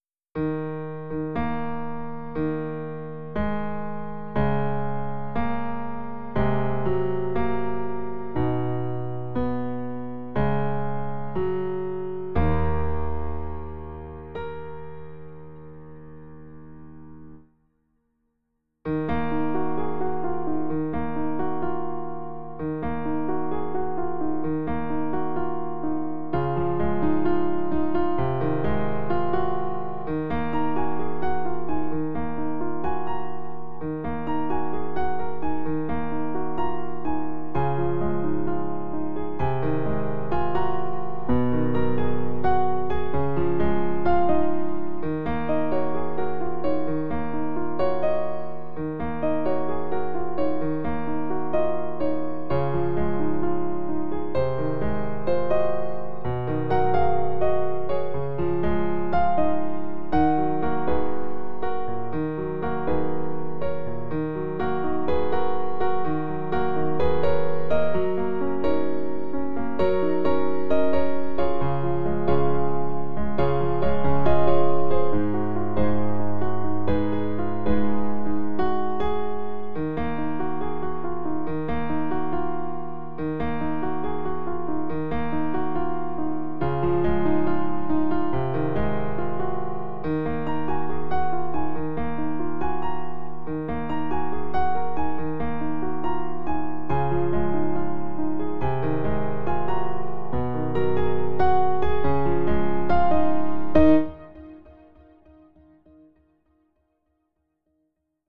Title Penny For Your Thoughts Opus # 629 Year 2025 Duration 00:01:46 Self-Rating 4 Description Hopefully I'm not overpaying. Piano solo. mp3 download wav download Files: wav mp3 Tags: Solo, Piano Plays: 462 Likes: 10